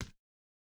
Bare Step Stone Hard A.wav